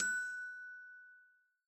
bell.ogg